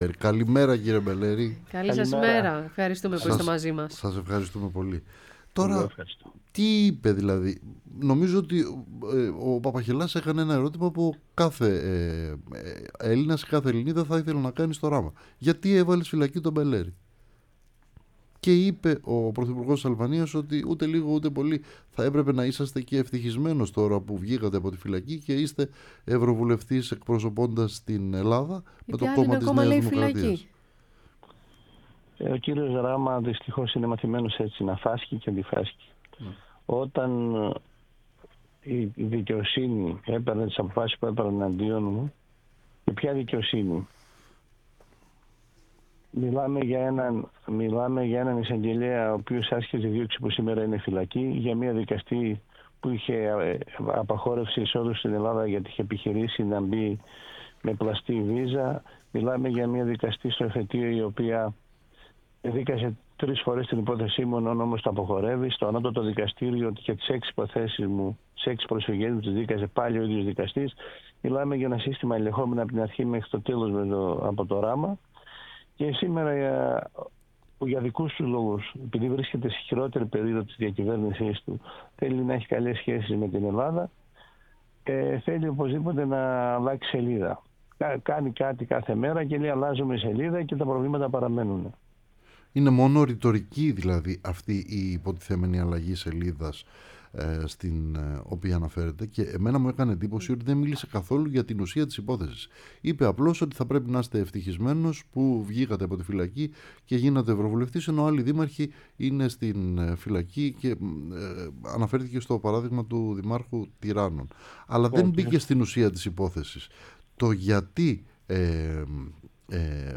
Ο Φρέντι Μπελέρης, Ευρωβουλευτής ΝΔ, μίλησε στην εκπομπή “Ραντάρ”